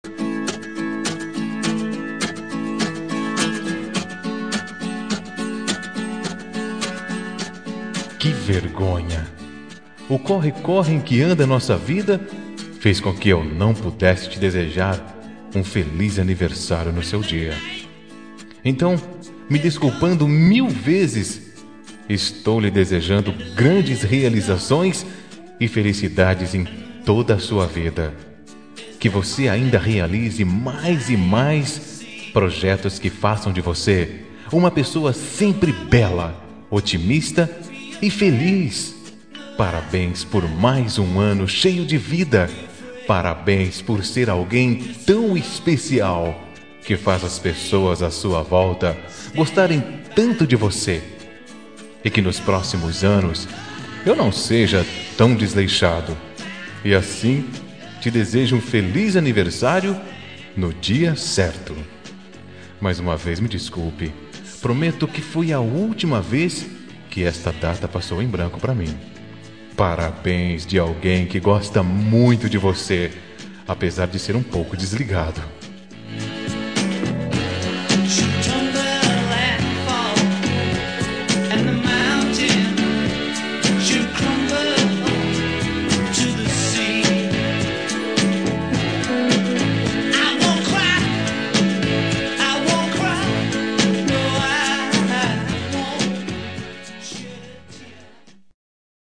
Aniversário Atrasado – Voz Masculina – Cód: 2493